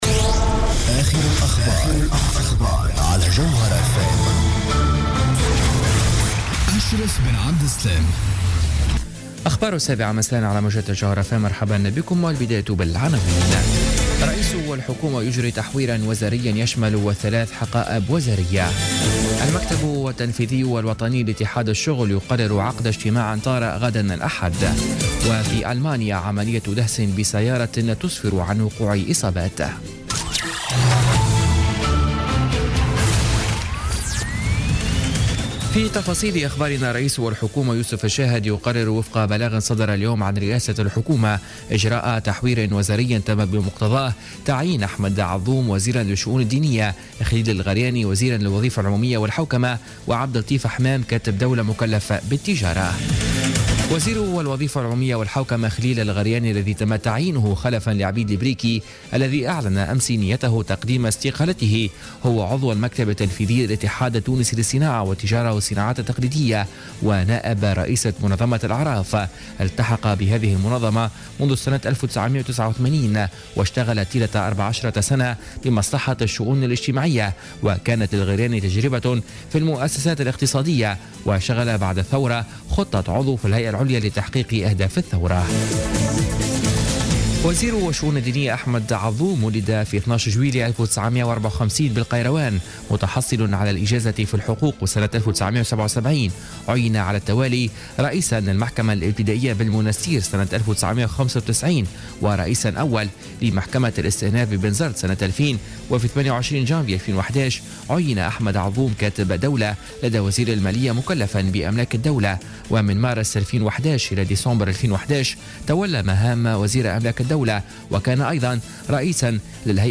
نشرة أخبار السابعة مساء ليوم السبت 25 فيفري 2017